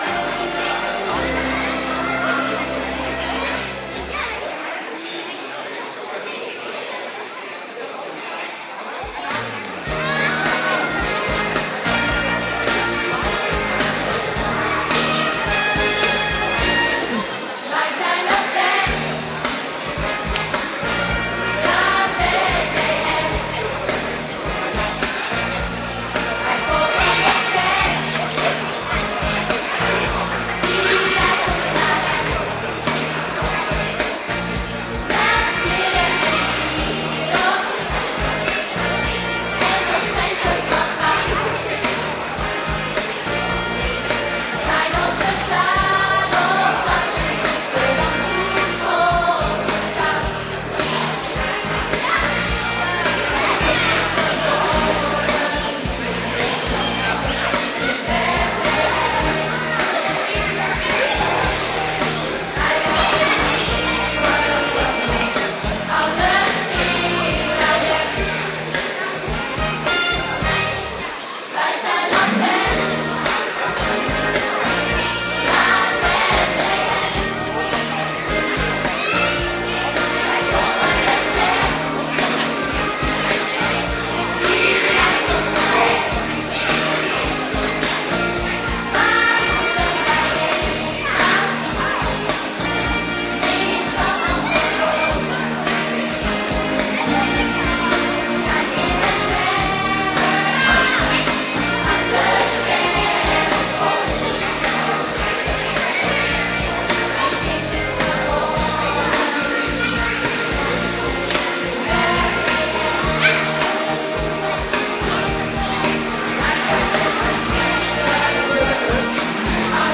Kerkdiensten
Een superleuke dienst speciaal voor jullie!